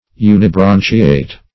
Search Result for " unibranchiate" : The Collaborative International Dictionary of English v.0.48: Unibranchiate \U`ni*bran"chi*ate\, a. [Uni- + branchiate.]
unibranchiate.mp3